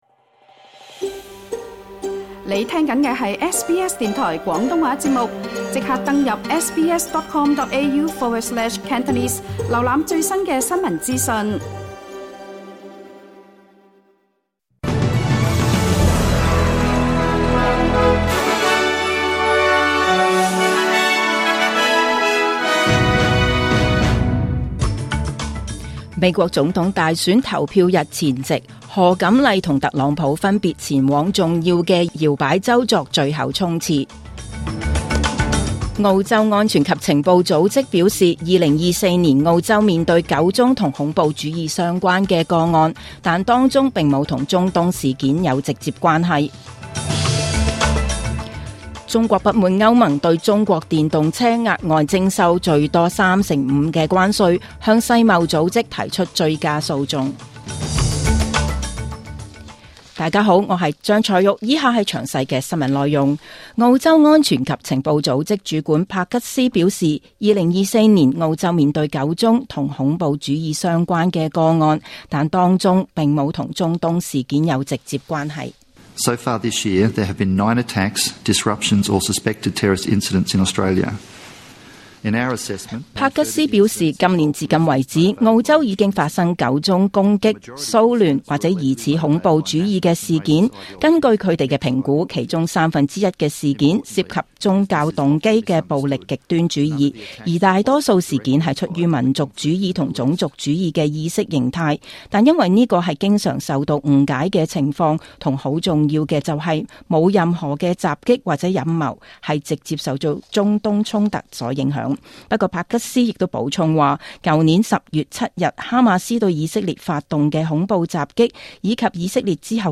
2024 年 11 月 5 日 SBS 廣東話節目詳盡早晨新聞報道。